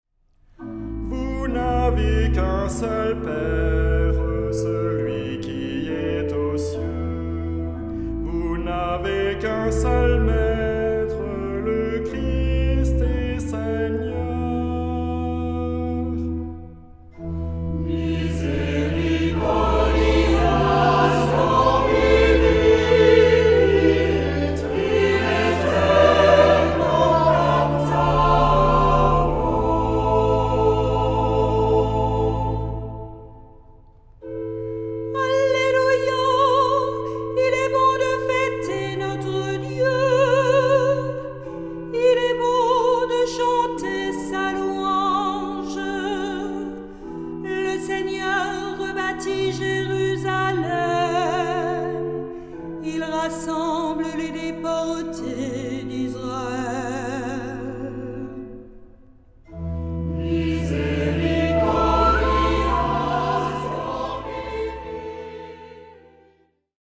Genre-Style-Forme : Sacré ; Tropaire ; Psaume
Caractère de la pièce : recueilli
Type de choeur : SATB  (4 voix mixtes )
Instruments : Orgue (1) ; Instrument mélodique (1)
Tonalité : la mineur